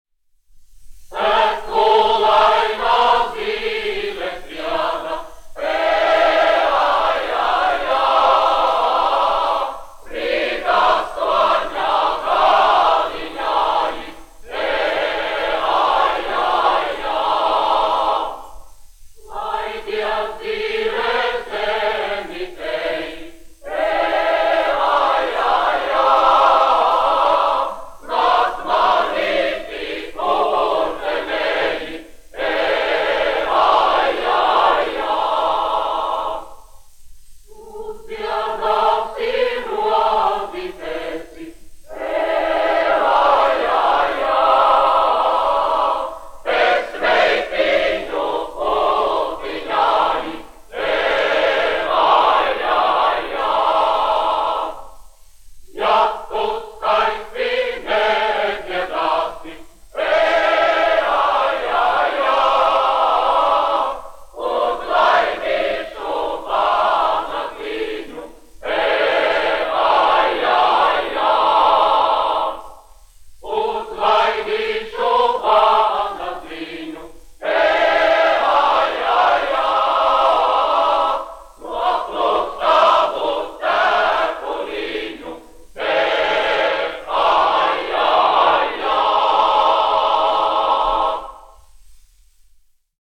Emilis Melngailis, 1874-1954, aranžētājs
Valsts Akadēmiskais koris "Latvija", izpildītājs
1 skpl. : analogs, 78 apgr/min, mono ; 25 cm
Latviešu tautasdziesmas
Kori (jauktie)
Skaņuplate